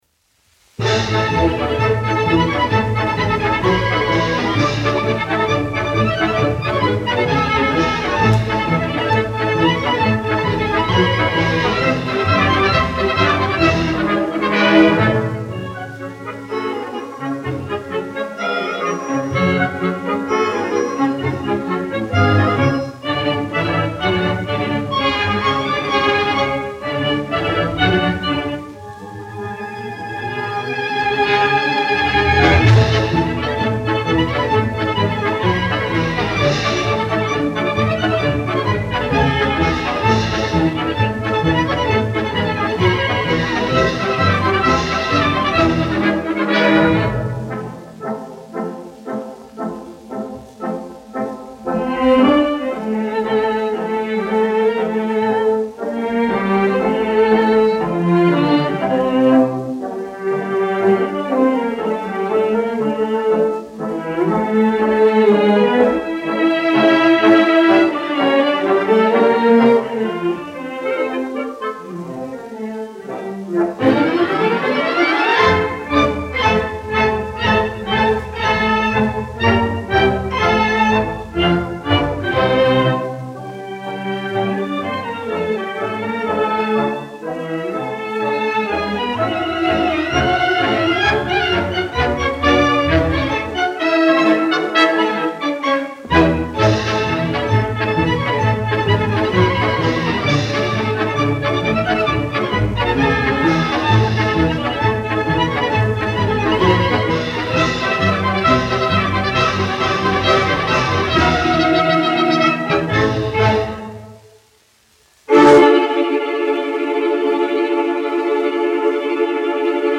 Staatskapelle Berlin, izpildītājs
Max von Schillings, diriģents
1 skpl. : analogs, 78 apgr/min, mono ; 25 cm
Operas--Fragmenti
Skaņuplate